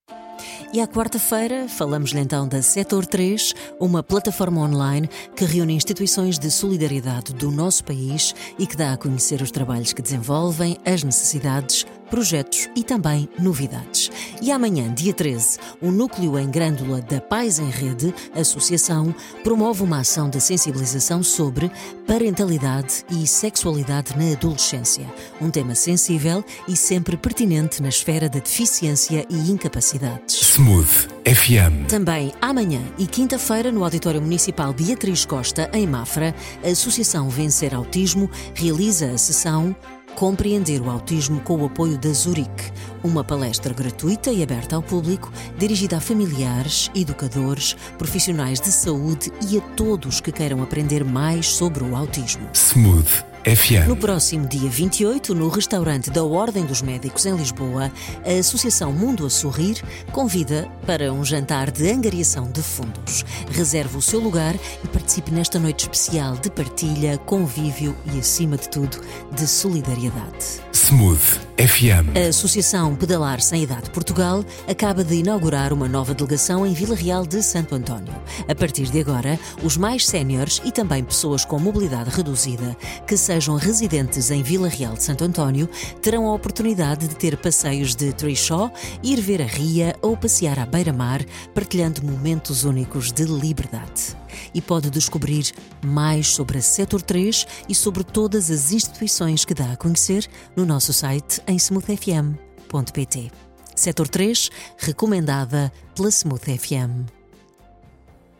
12 março 2025 Flash Smooth FM Pais em Rede | Vencer Autismo | Mundo a Sorrir | Pedalar Sem Idade Portugal partilhar Facebook Twitter Email Apontamento rádio sobre a atividade desenvolvida por entidades-membros do Diretório Sector 3, que vai para o ar todas as quartas-feiras, às 8h, às 13h e às 17h.
Oiça a gravação do spot rádio no ficheiro anexo